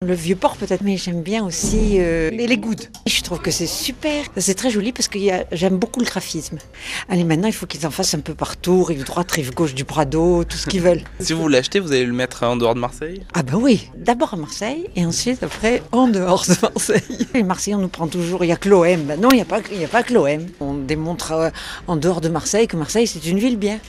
cliente enthousiaste passait par la boutique (et n’est même pas de la famille de l’artiste, c’est pour dire)